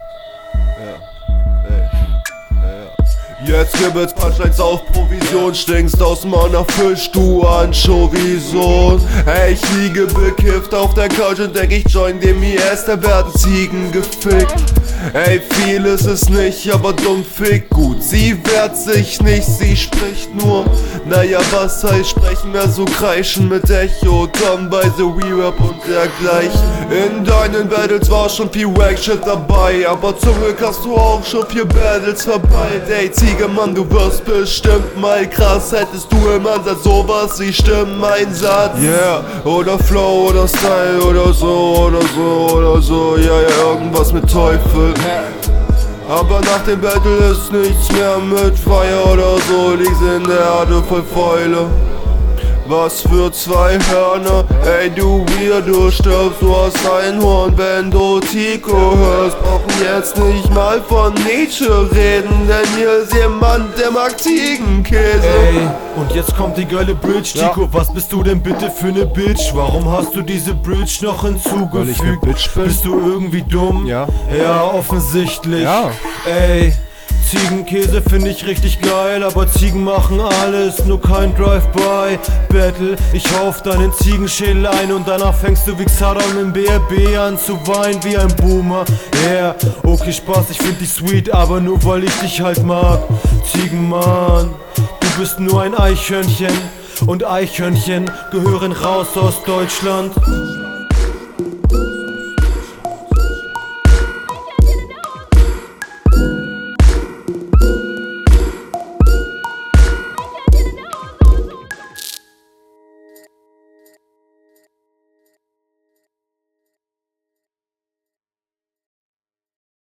Richtig seltsame Psycho/Lean-Stimmung :D Mag ich an sich aber ist leider vom Style her nicht …
Beat ist echt top.